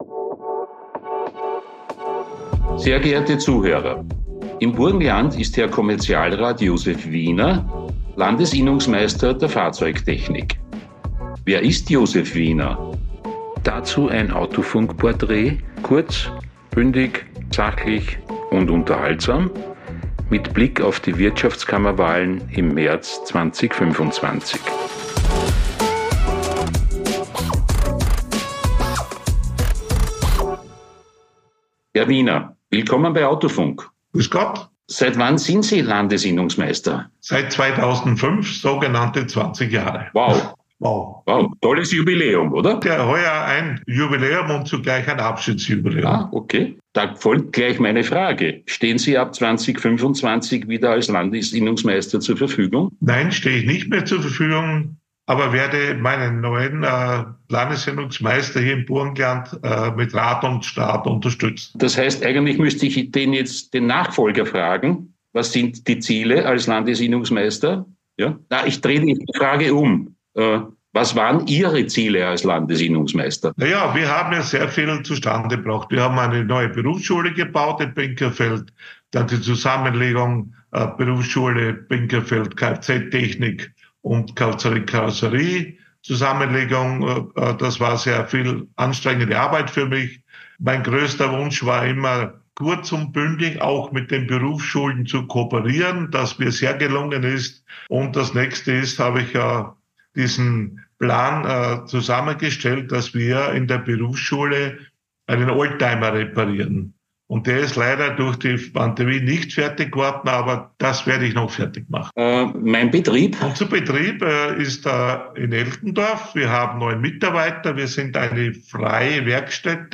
Gespräch.